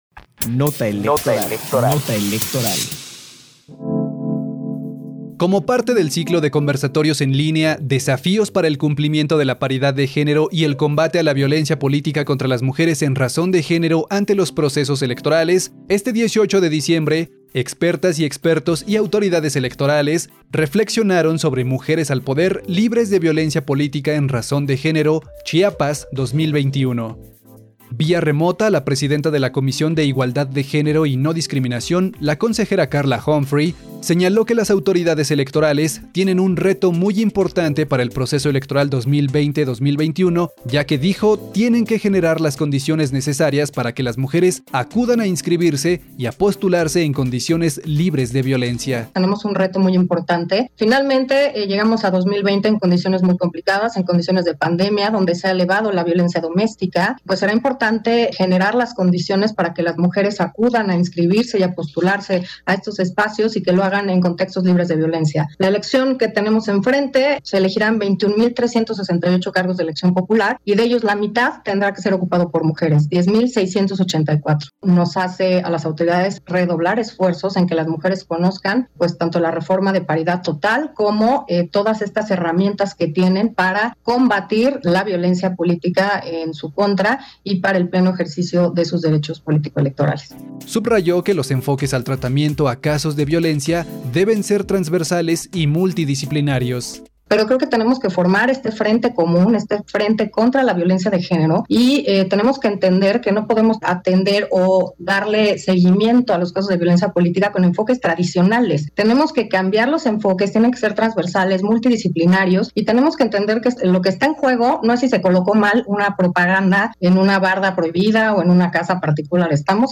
Participan consejeras en cuarto Conversatorio Mujeres al poder libres de violencia política en razón de género.